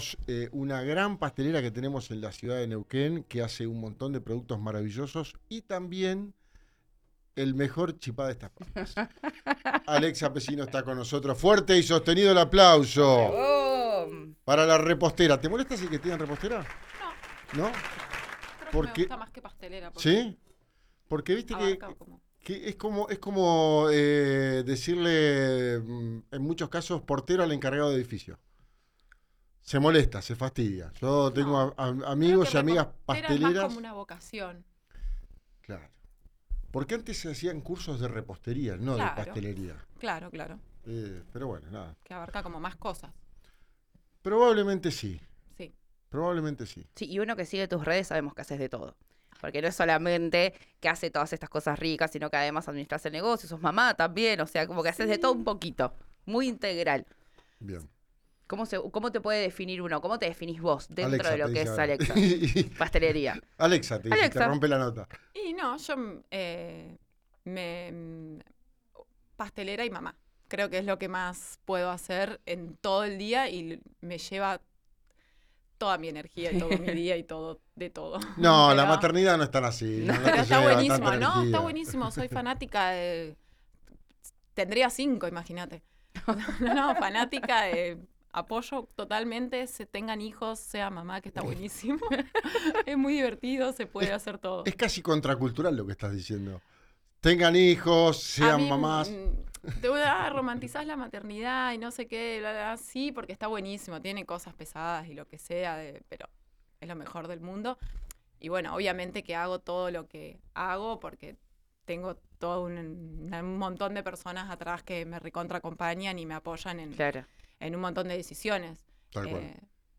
La repostera visitó RÍO NEGRO RADIO y conversó con «Ya es tiempo» sobre este boom y cómo aprovecharlo.